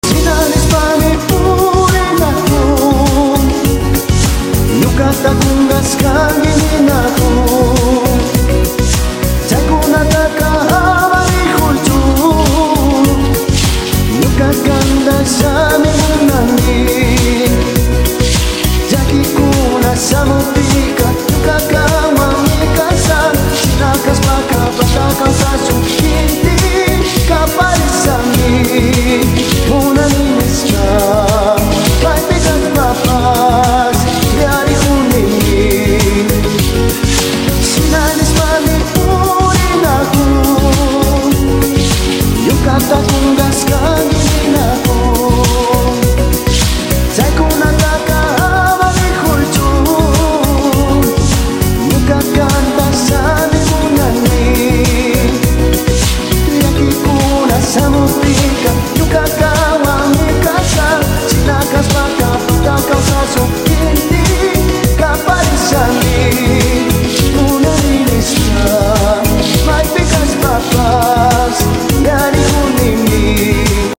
Superbe chanson d’amour andine